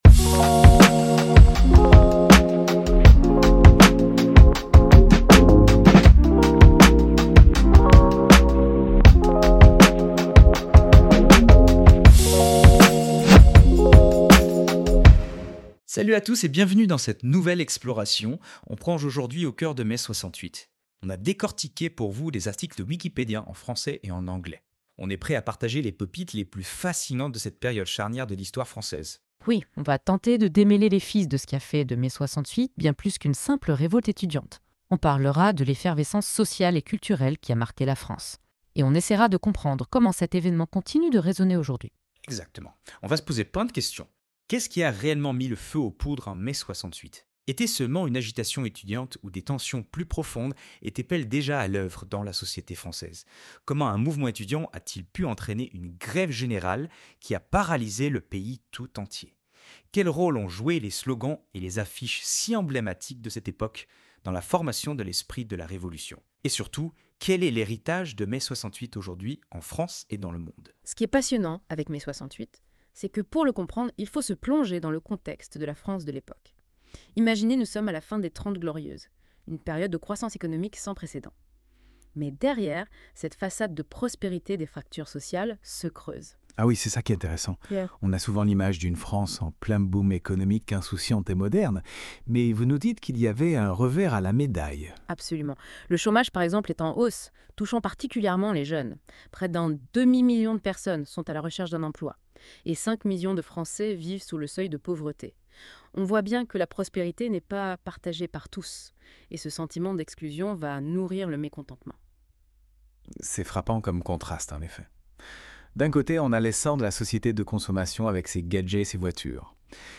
Notebook LM (IA)